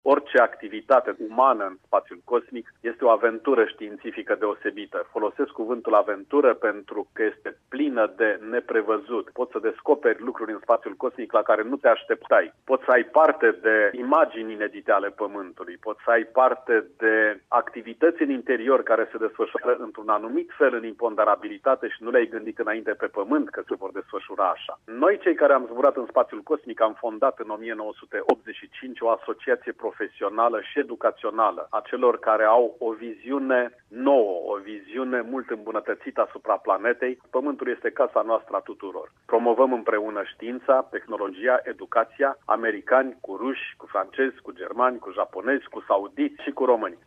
Dumitru Prunariu într-un interviu